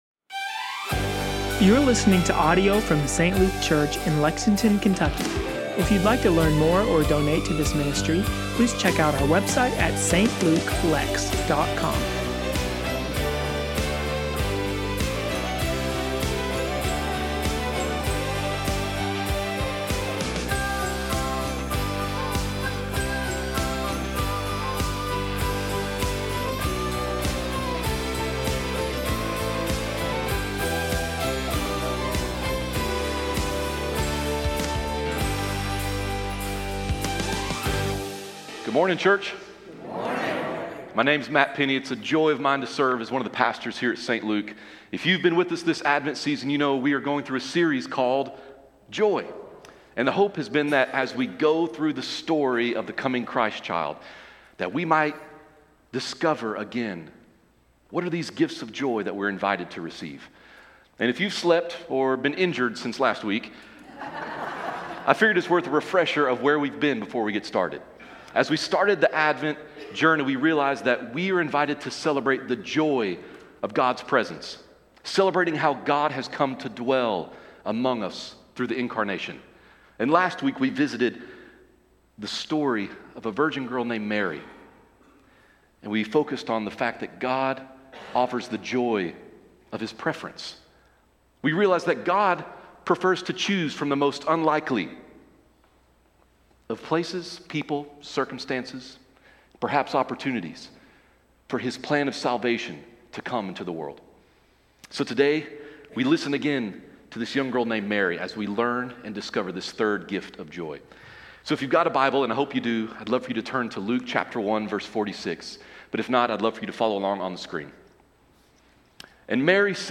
St. Luke Church Lexington – Sermons & Teachings